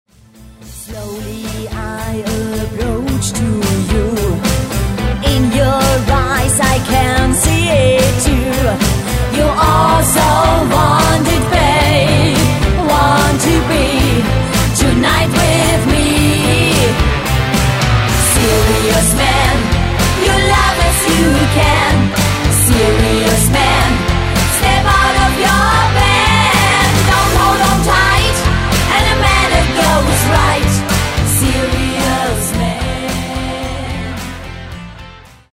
Stil: Rock-Pop
Krafvoller, hymnenhafter Poprock